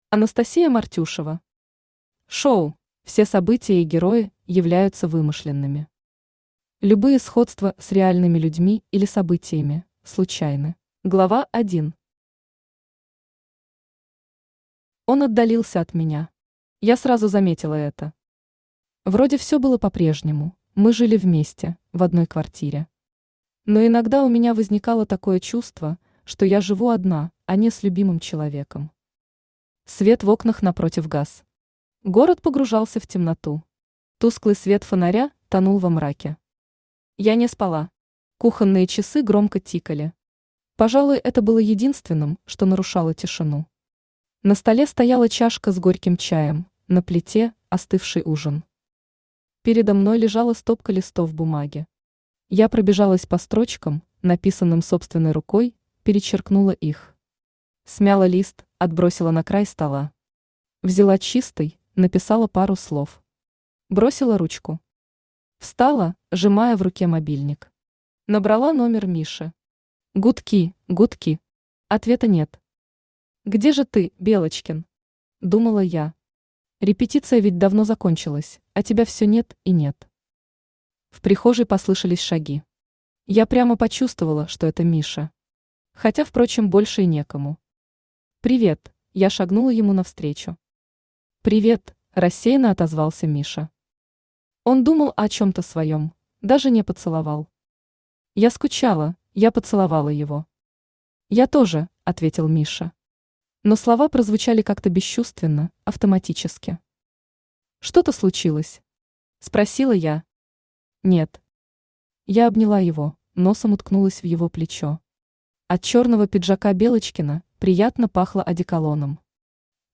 Аудиокнига Шоу | Библиотека аудиокниг
Aудиокнига Шоу Автор Анастасия Ивановна Мартюшева Читает аудиокнигу Авточтец ЛитРес.